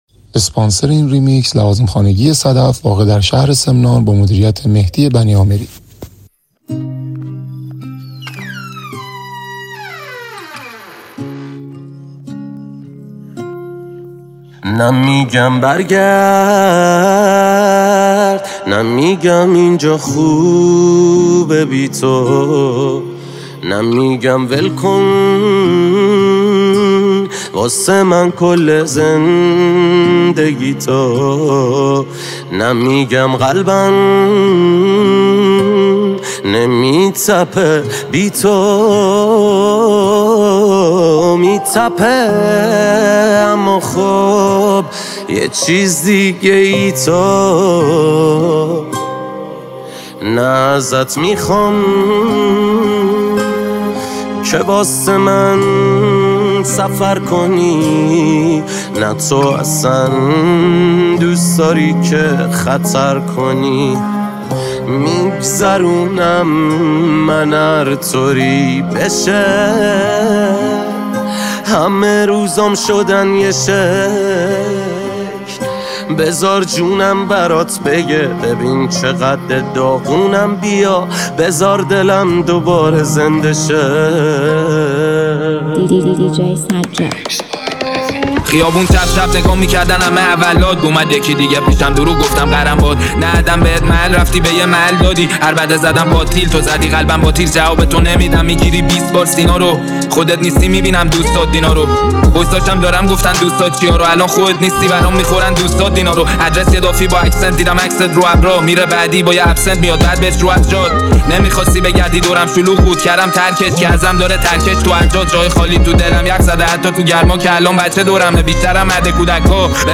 ریمیکس ترکیبی رپ